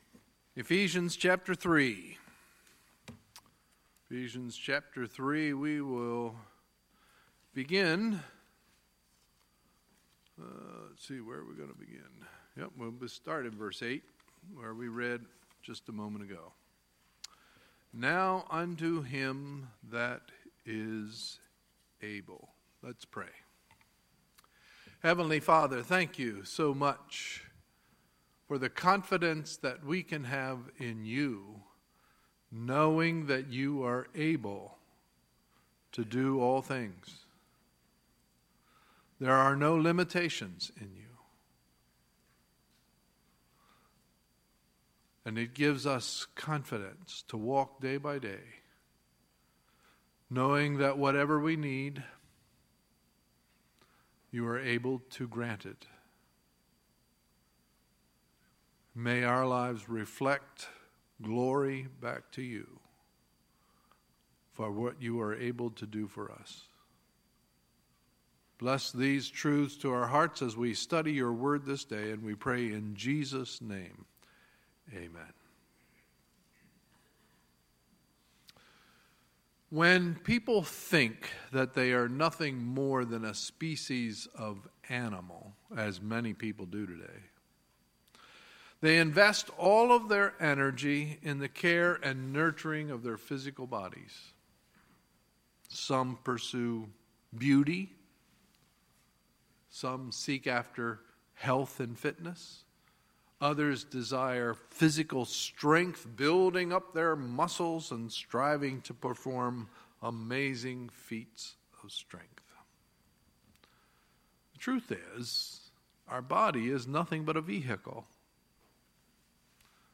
Sunday, August 20, 2017 – Sunday Morning Service
Sermons